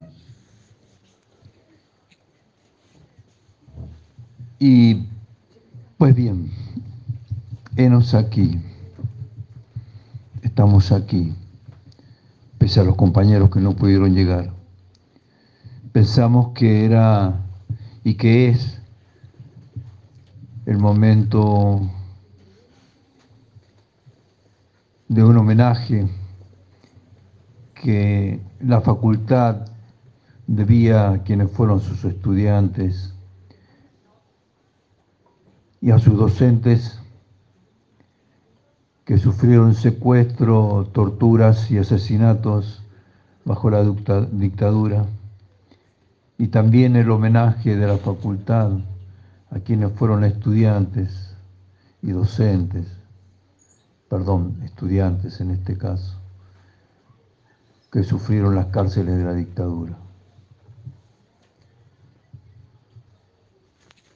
Este miércoles 11 de octubre se llevó adelante las V Jornadas de Derechos Humanos en los 50 años de la Universidad en el Salón de Actos de la Facultad de Ciencias Sociales.